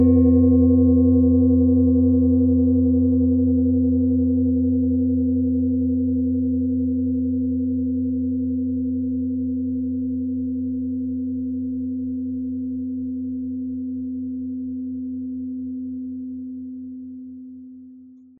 Planetenton
Ein unpersönlicher Ton.
Den passenden Schlägel erhalten Sie kostenlos mitgeliefert, er lässt die Klangschale harmonisch und wohltuend ertönen.
MaterialBronze